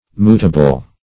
Mootable \Moot"a*ble\, a. Capable of being mooted.